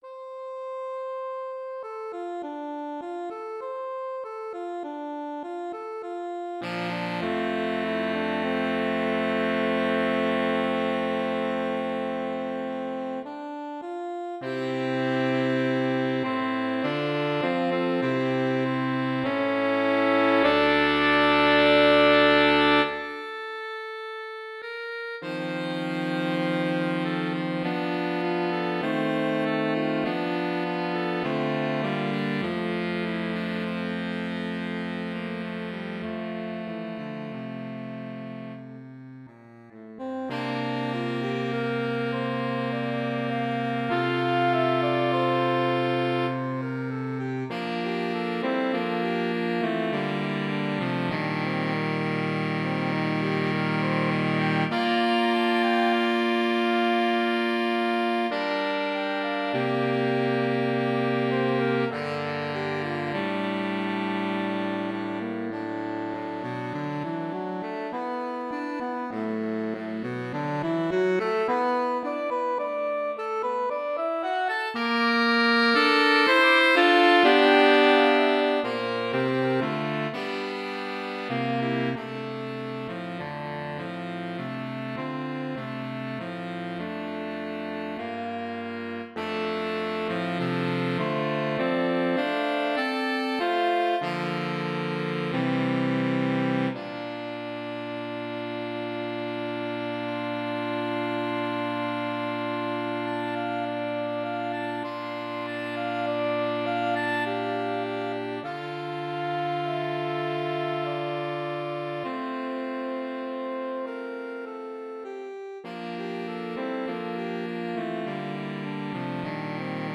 mp3 SCORE Girl With The Flaxen Hair V8 [Claude Debussy] 3 SATB. A famous beautiful etude.